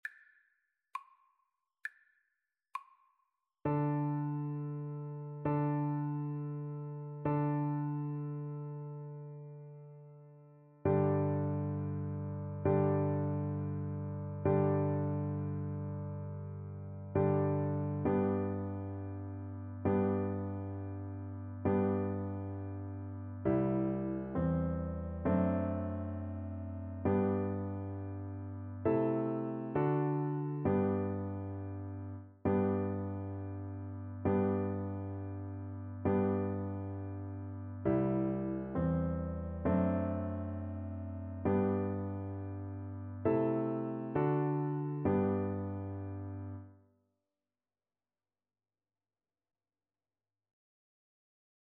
6/8 (View more 6/8 Music)
Allegretto
Classical (View more Classical Violin Music)